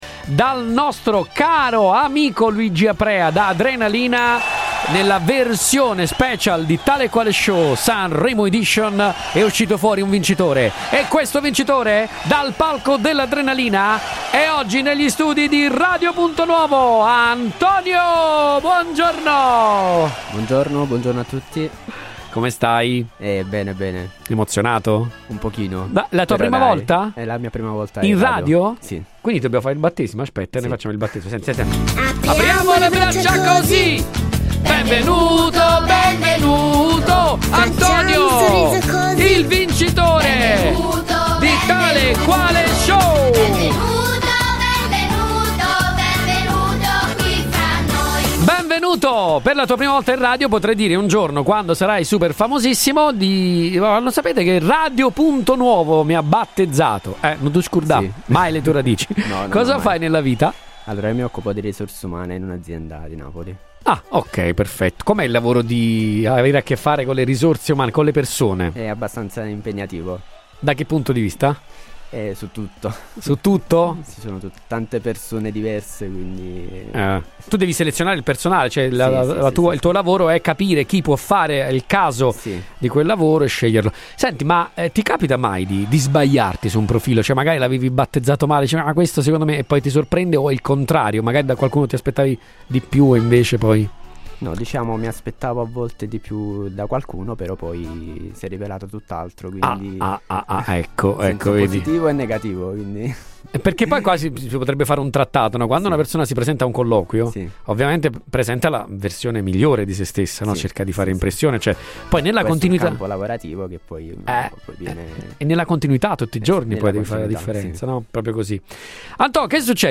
una performance live da brividi